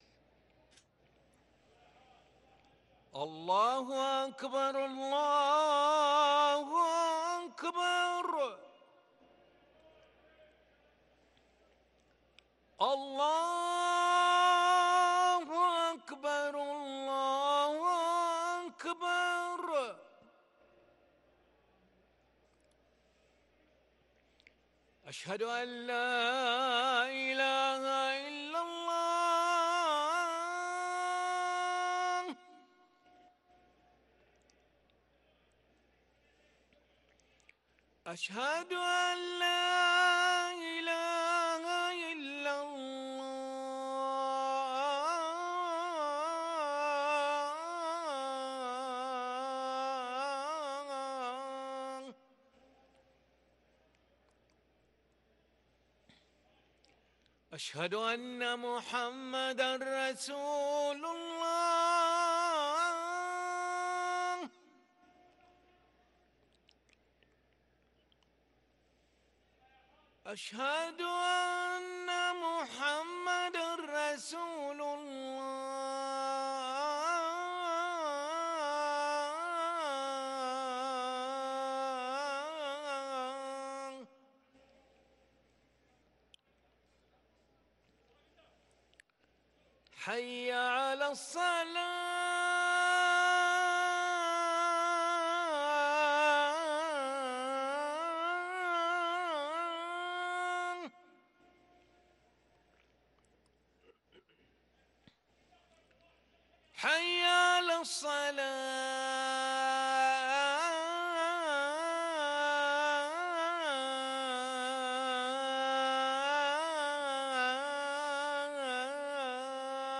أذان العشاء للمؤذن علي ملا الأحد 3 جمادى الأولى 1444هـ > ١٤٤٤ 🕋 > ركن الأذان 🕋 > المزيد - تلاوات الحرمين